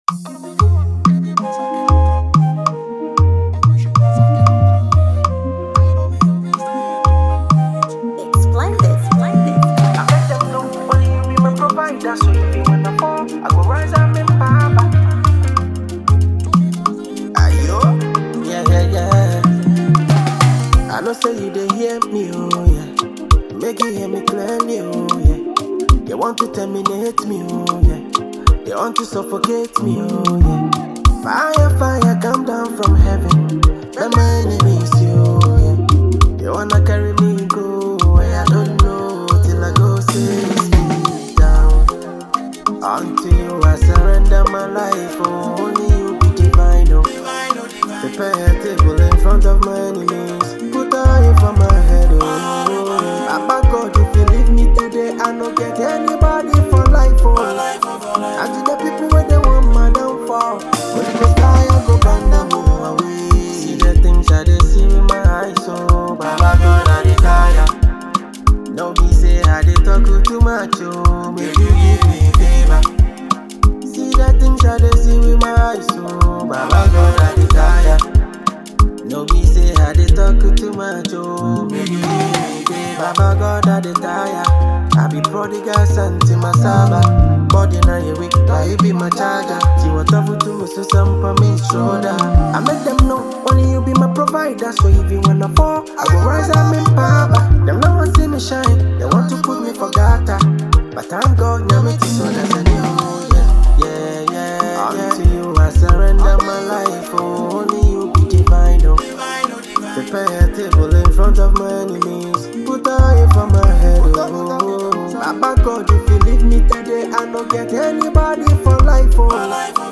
highlife song
• Genre: Nigerian Street Music/Afrobeat
soulful Afrobeat tune